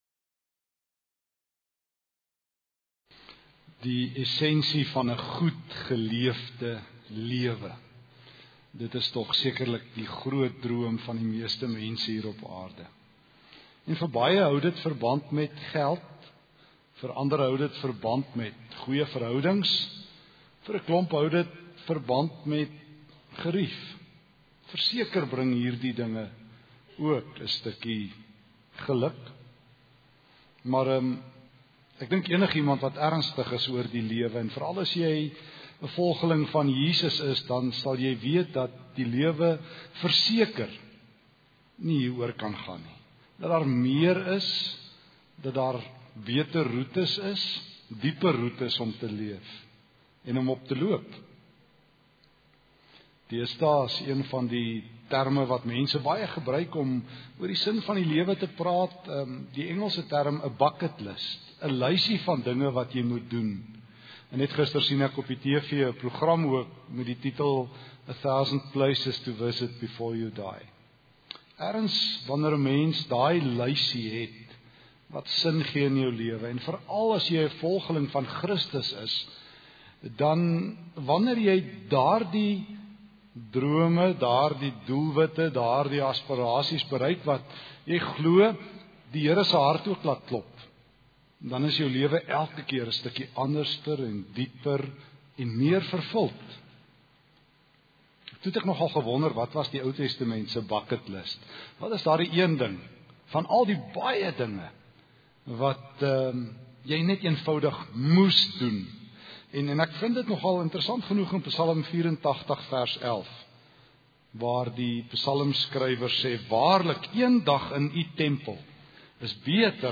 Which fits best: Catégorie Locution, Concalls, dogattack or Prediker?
Prediker